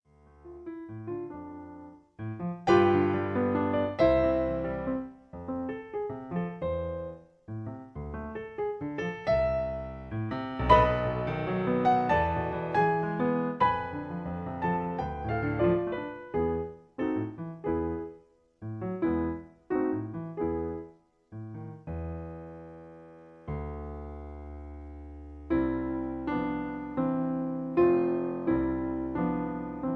Sonata No 3 for Violin and Piano in D Minor
Piano accompaniment track